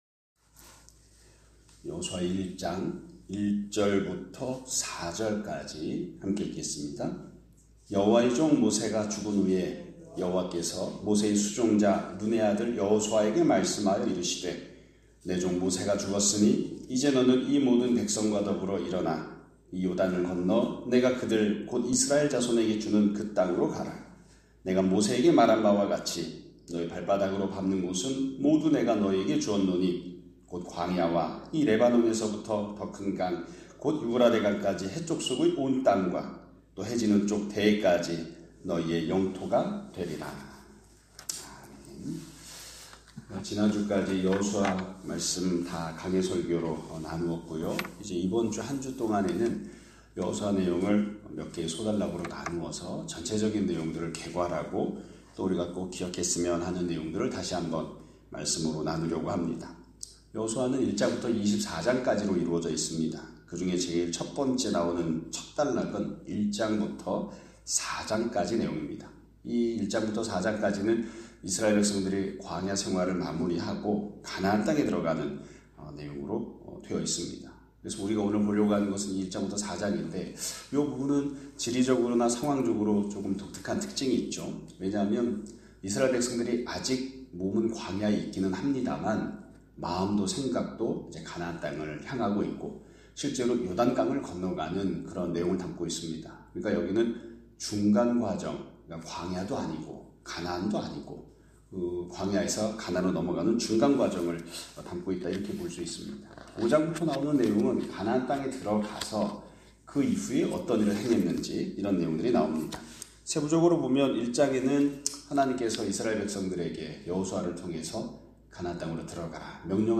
2025년 3월 10일(월요일) <아침예배> 설교입니다.